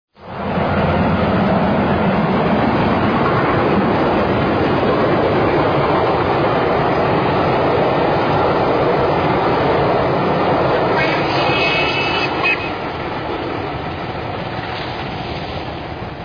Sounds of L. M. S. steam locomotives